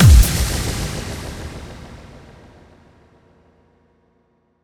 Index of /musicradar/cinematic-drama-samples/Impacts
Impact 03.wav